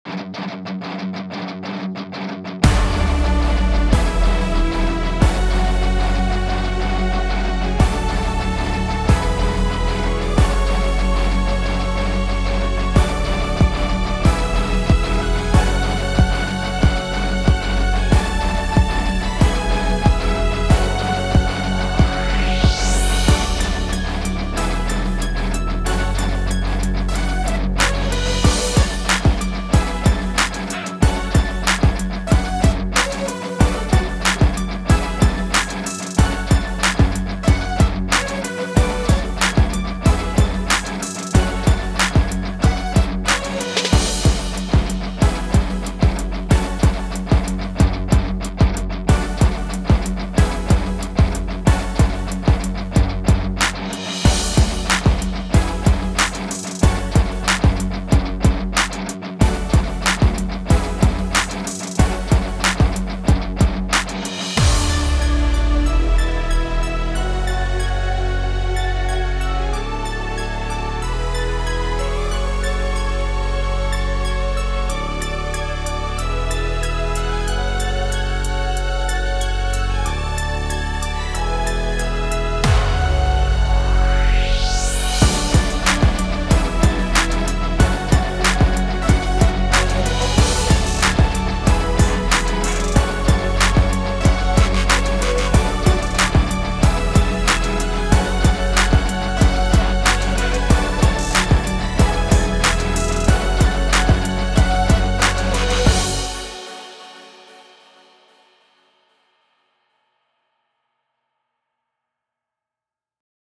Emotion: Hi energy, sports, hip hop, energetic, uptempo